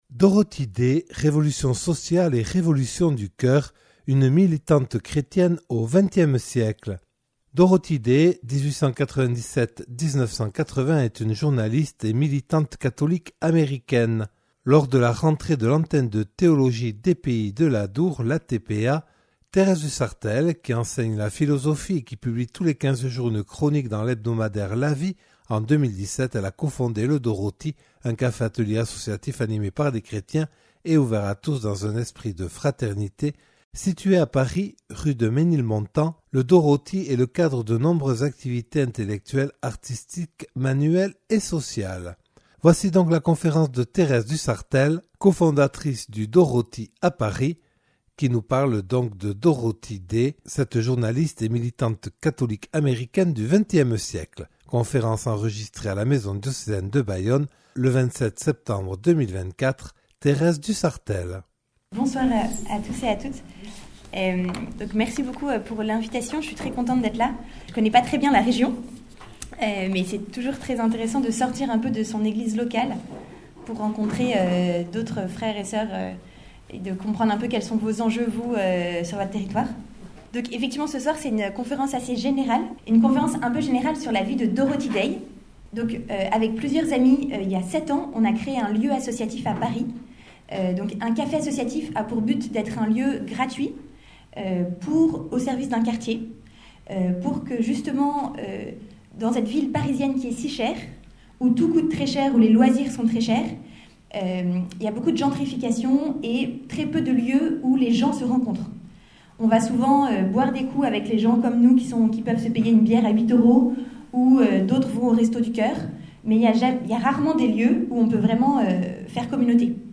Conférence organisée par l’Antenne de Théologie des Pays de l’Adour le 27 septembre 2024 à la Maison diocésaine de Bayonne.